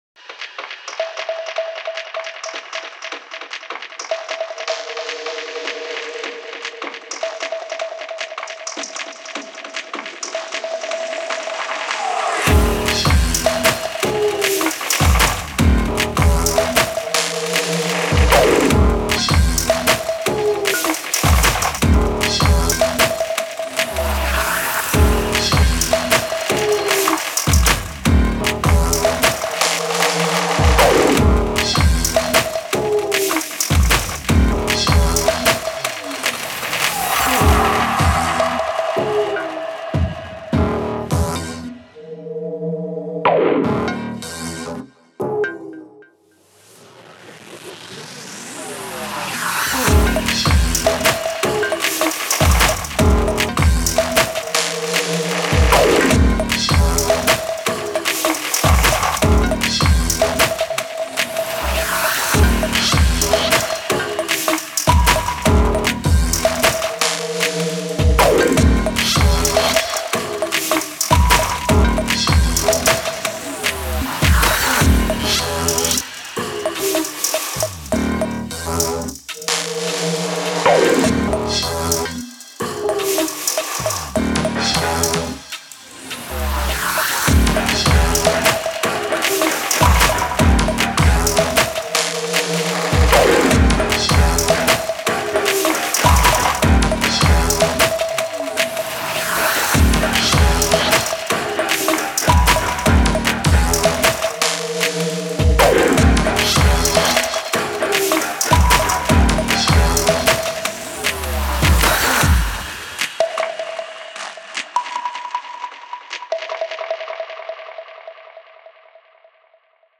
Mechanical rhythms driving to the beat.
Precise stabs and cuts over restless percussive elements.